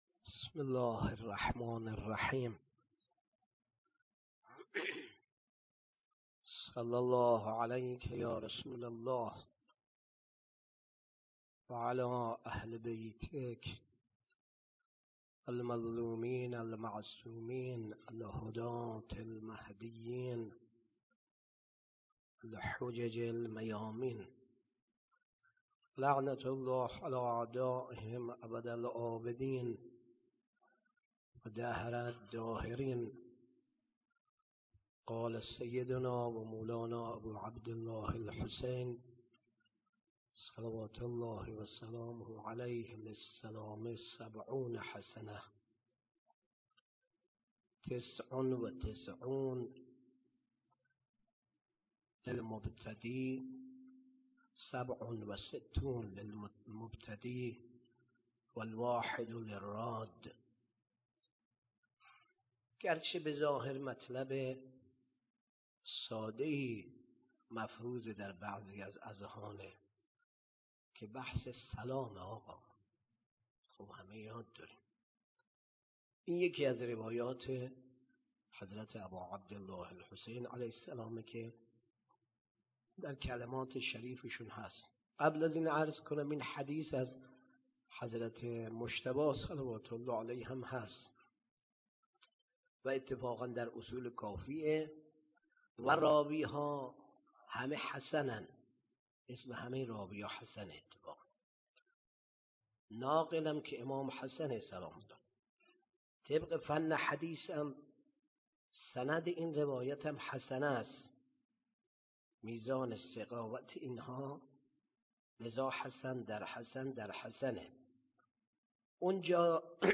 18 صفر 96 - تکیه حاج سیدحسن - سخنرانی